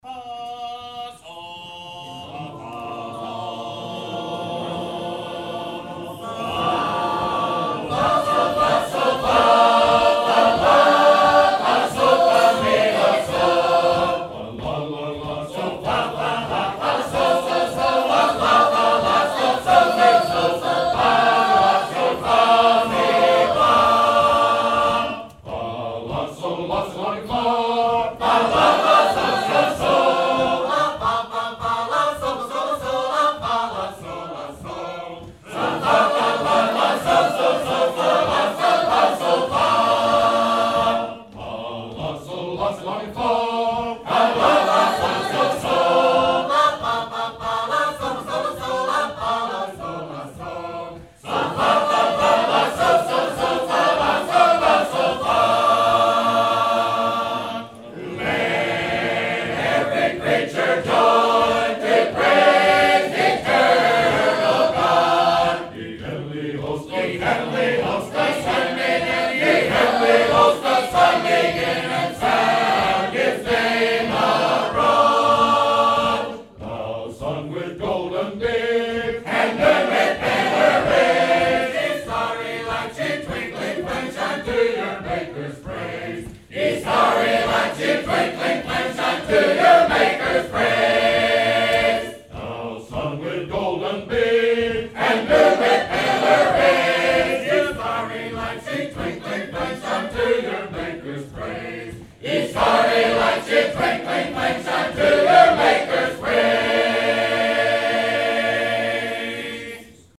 Reid Memorial – October 21, 2018 | Southern Field Recordings